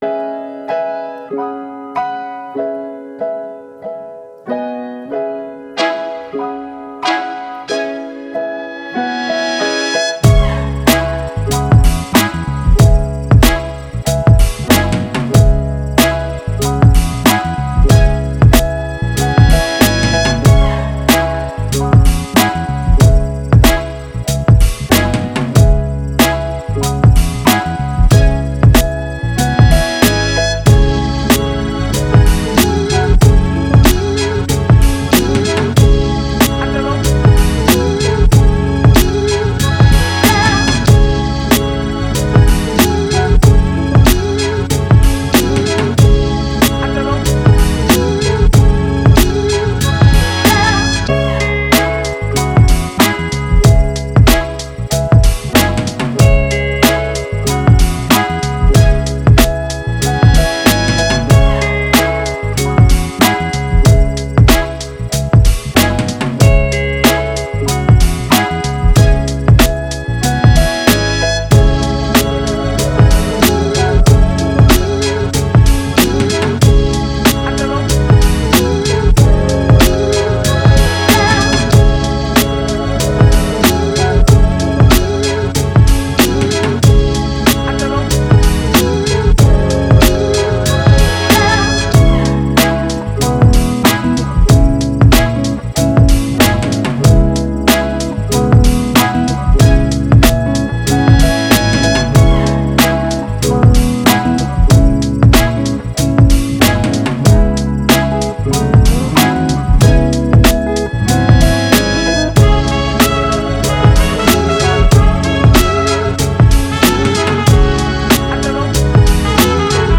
Hip Hop, 90s, Boom Bap, Upbeat